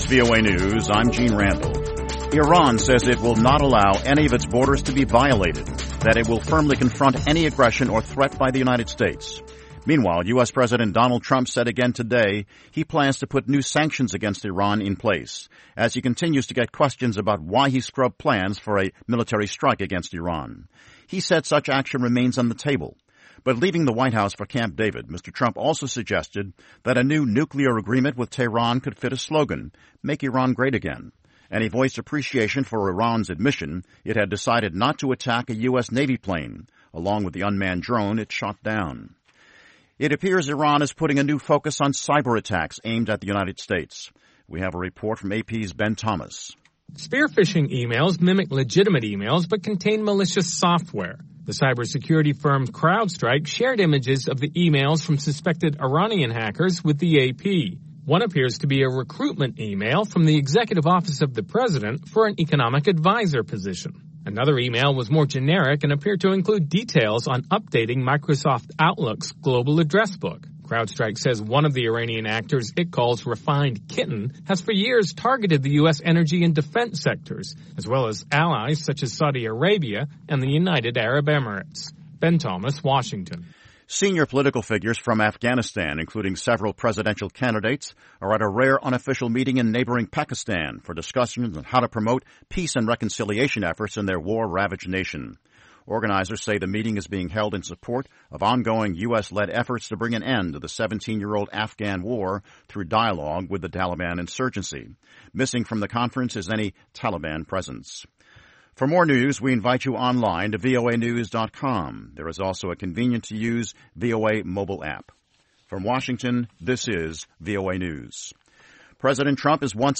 We bring you reports from our correspondents and interviews with newsmakers from across the world.
Tune in at the top of every hour, every day of the week, for the 5-minute VOA Newscast.